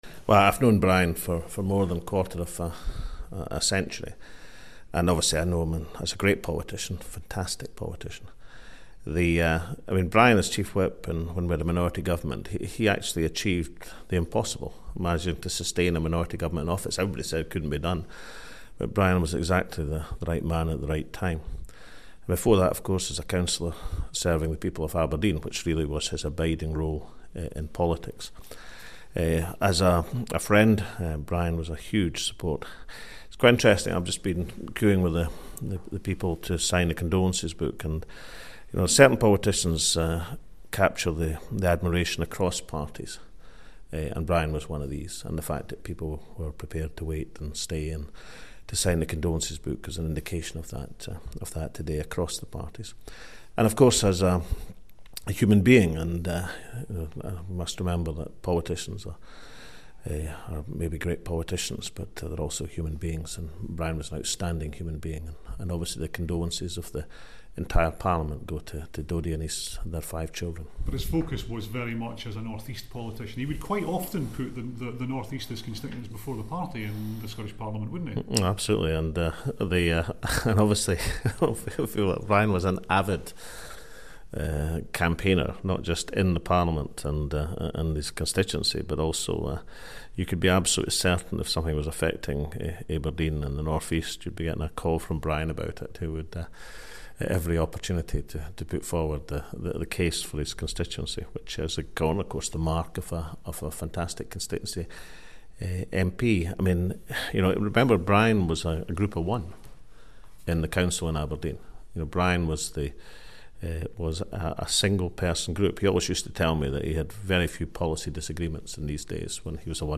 Alex Salmond speaking about Brian Adam